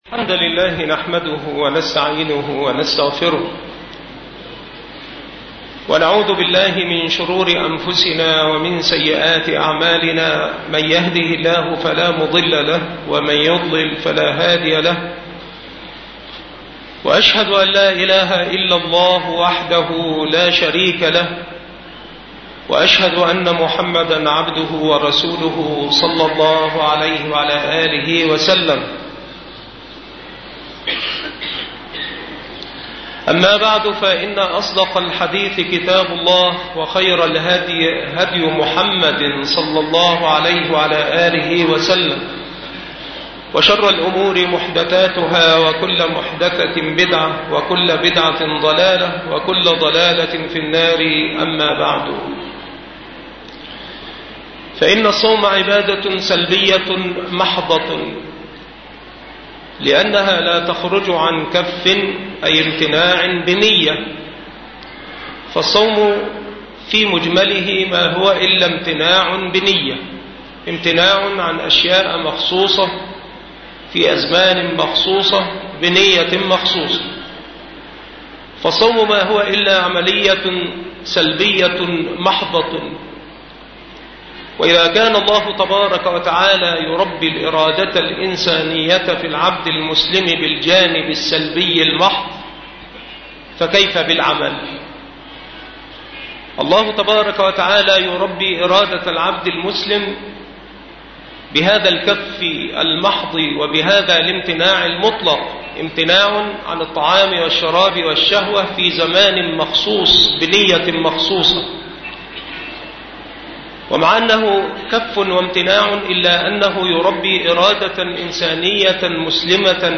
المحاضرة
مكان إلقاء هذه المحاضرة بالجمعية الشرعية بأشمون بمحافظة المنوفية